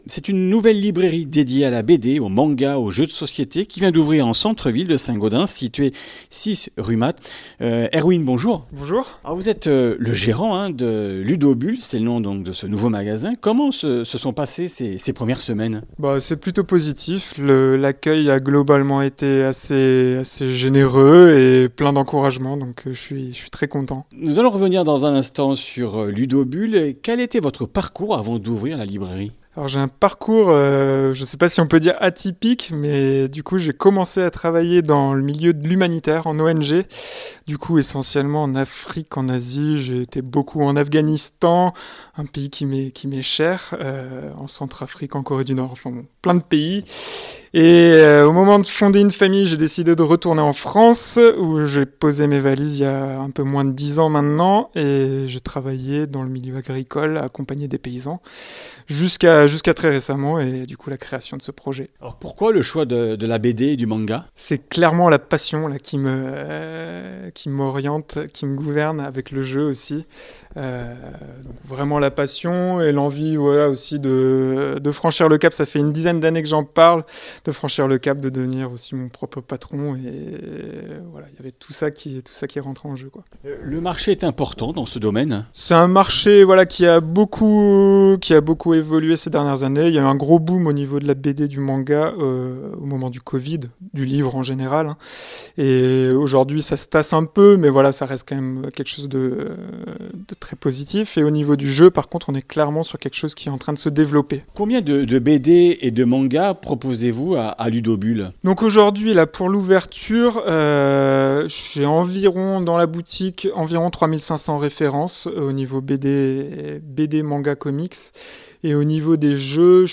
Comminges Interviews du 12 nov.
Une émission présentée par